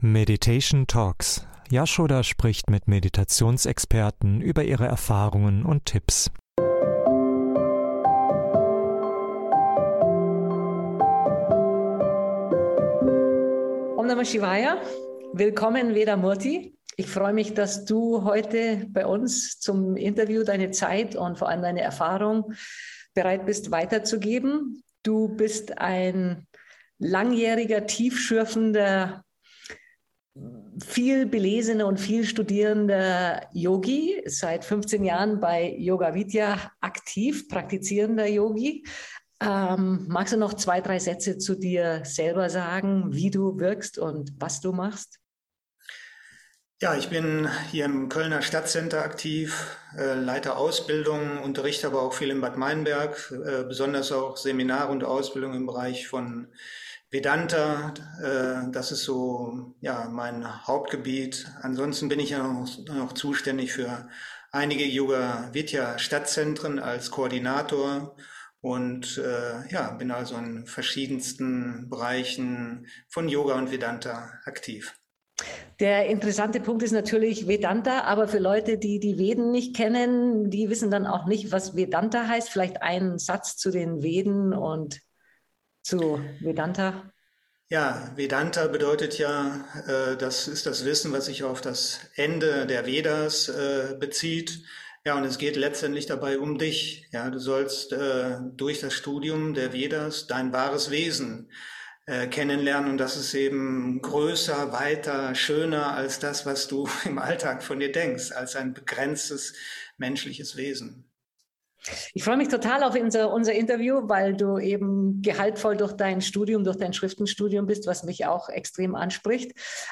So unterstützen dich die Interviews bei der Vertiefung deiner eigenen Meditationspraxis.